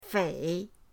fei3.mp3